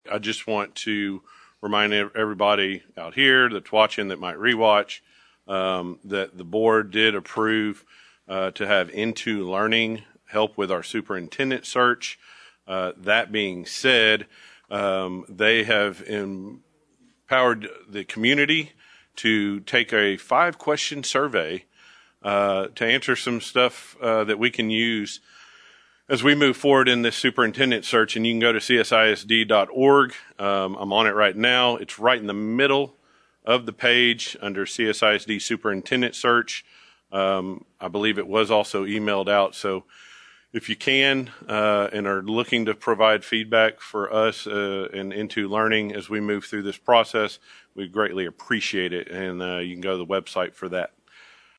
Click below for comments from Jeff Horak during the April 18, 2023 College Station ISD school board meeting.